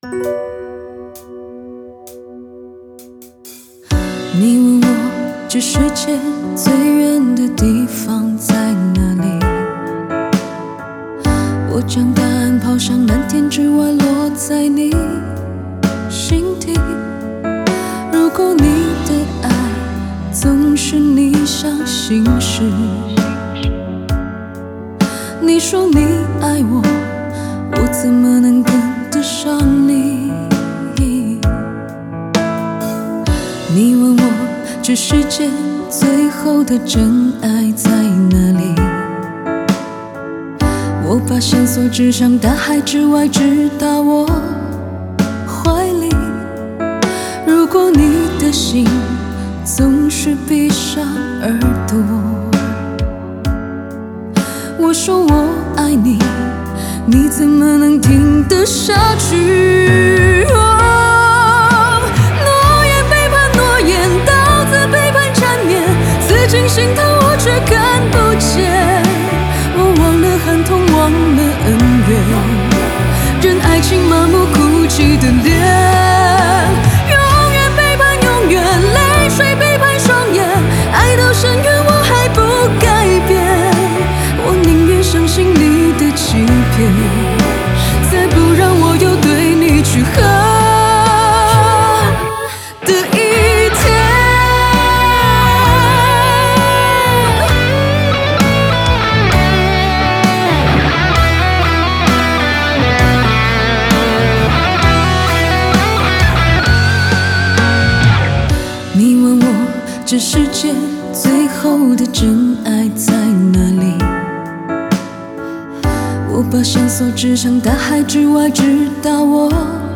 Ps：在线试听为压缩音质节选，体验无损音质请下载完整版
女声版